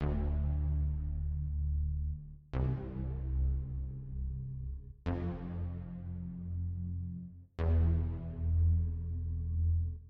描述：带效果的低音
声道立体声